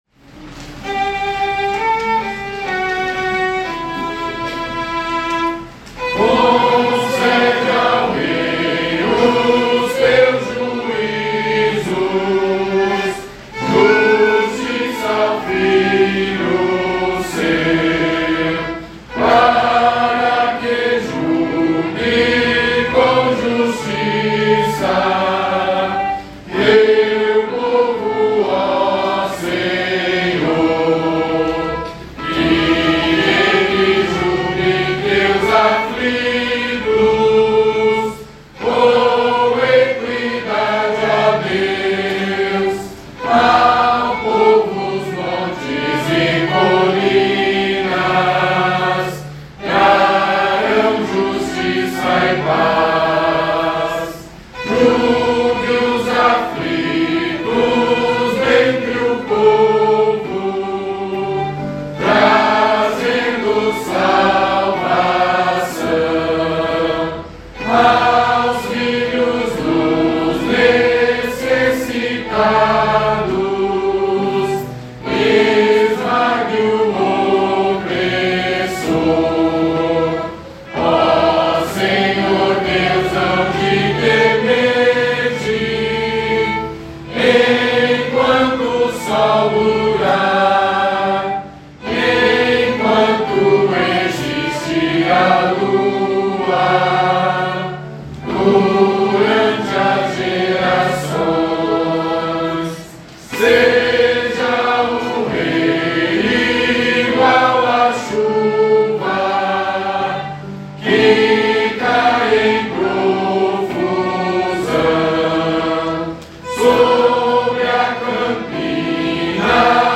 Modo: eólio
Harmonização: Claude Goudimel, 1564
salmo_72A_cantado.mp3